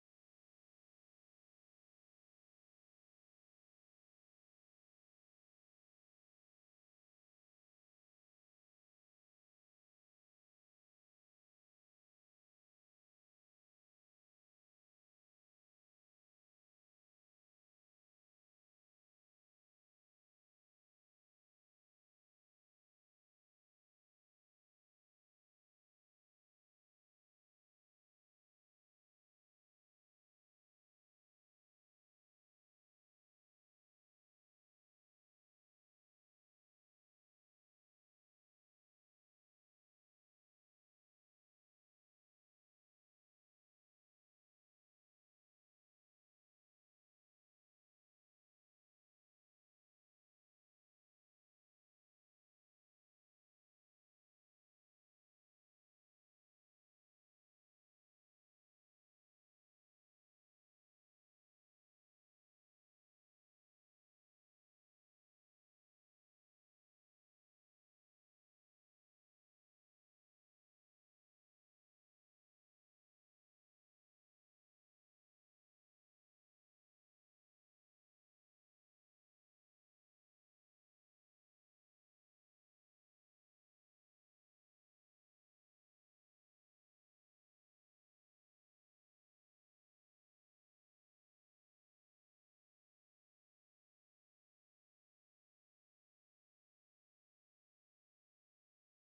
Umím: Voiceover
Mladý energický mužský hlas přesně pro Vás!